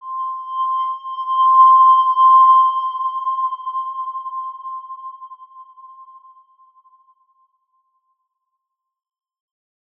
X_Windwistle-C5-pp.wav